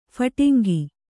♪ phaṭingi